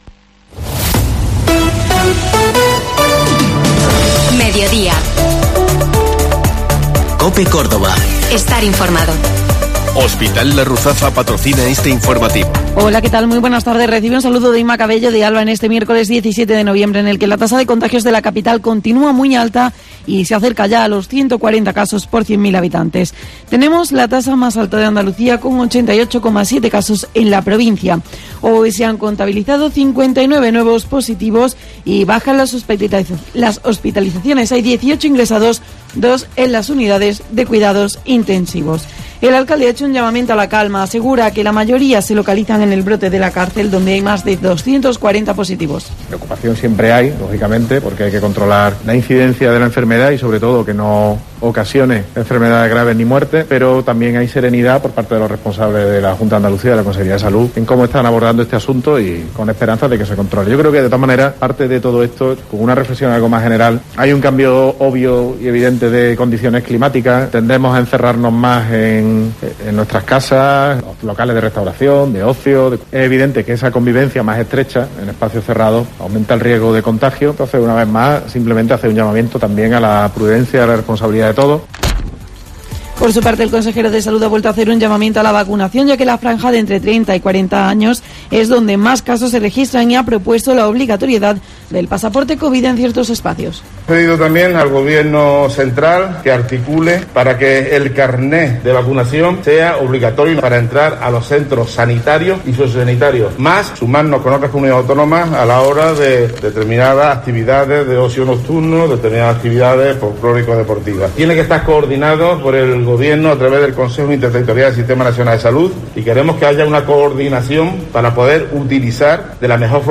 Informativo Mediodía COPE Córdoba
Te contamos las últimas noticias de Córdoba y provincia con los reportajes que más te interesan y las mejores entrevistas.